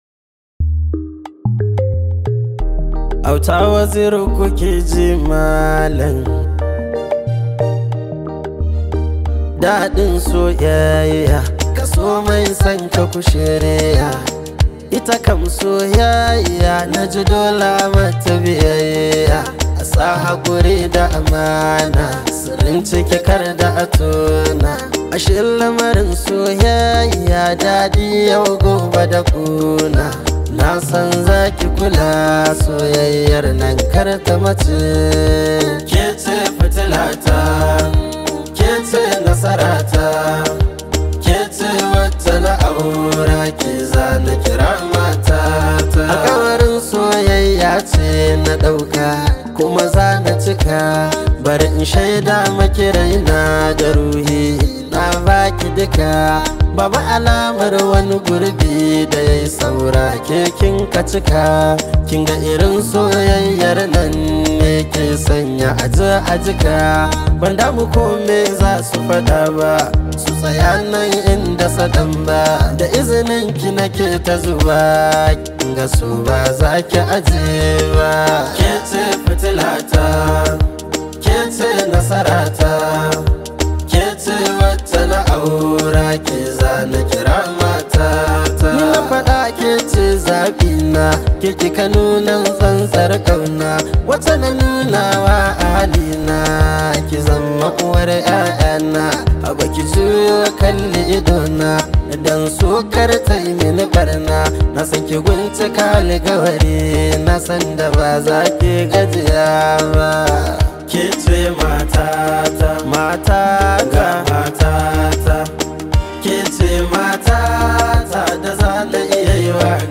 highly celebrated Hausa Singer